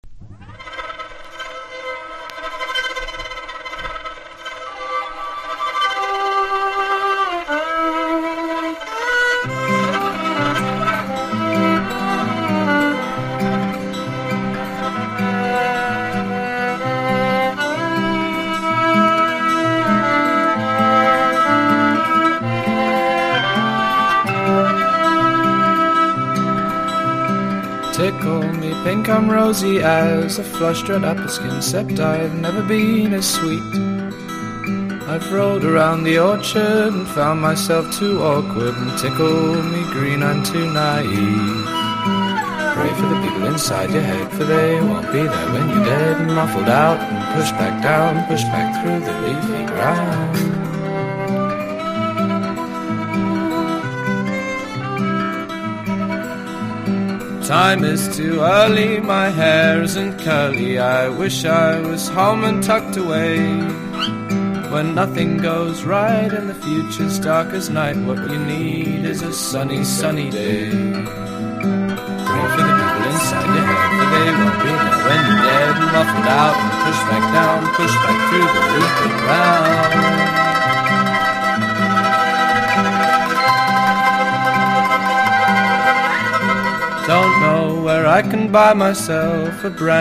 1. 00S ROCK >
SSW / FOLK
グレイト・ブリティッシュ・フォーク・ミュージック。